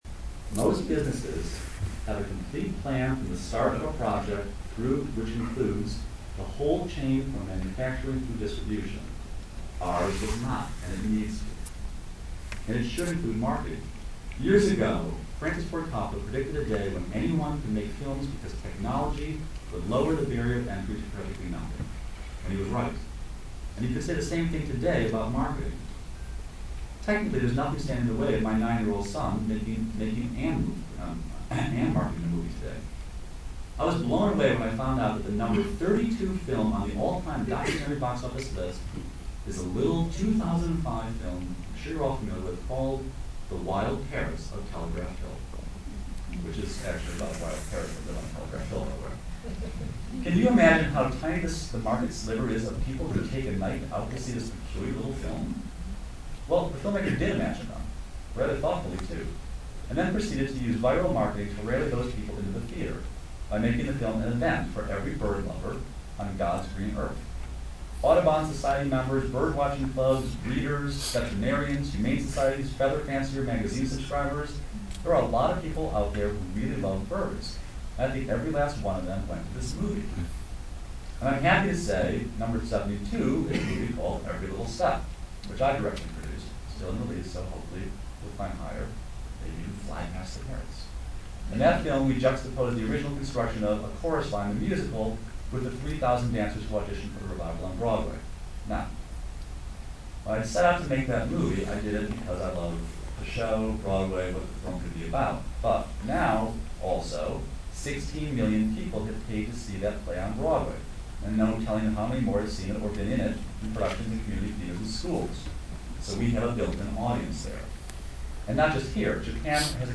Keynote at LA Film Fest's Financing Conference